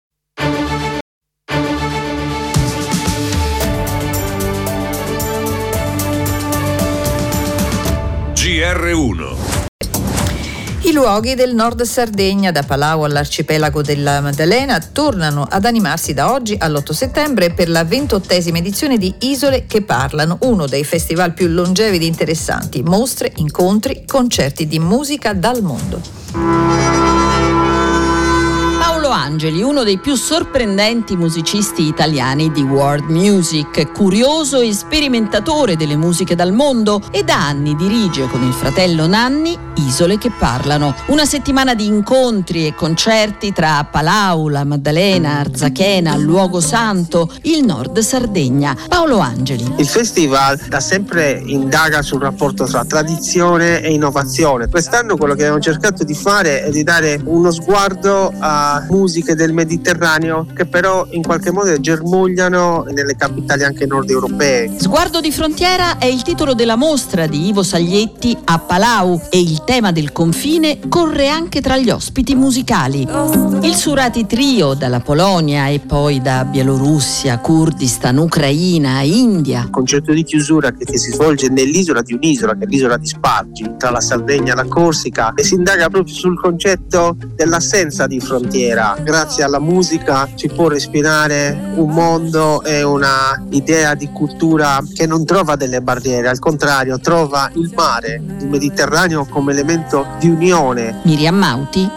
Servizio di RAI GR1 delle ore 10:00 e replica su RAI GR3 delle ore 18:45